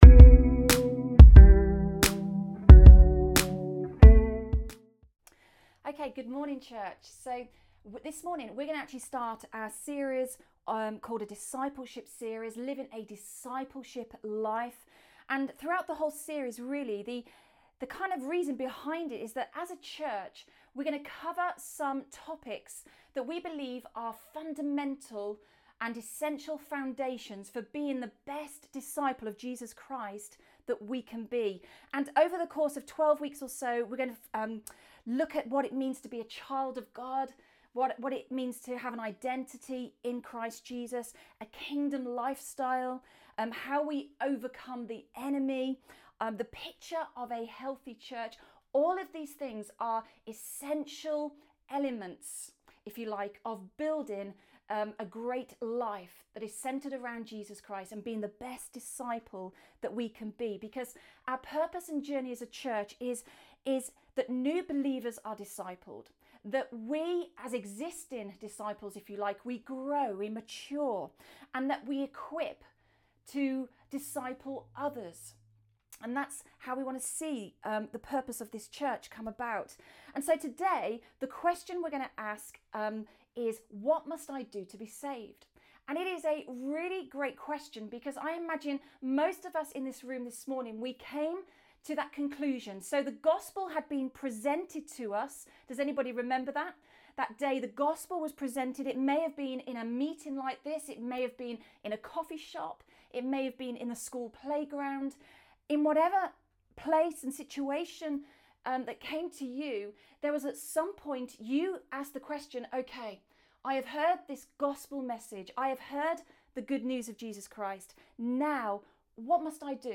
Rediscover Church Newton Abbot | Sunday Messages A Discipleship Series - Part 1 | What must I do to be Saved?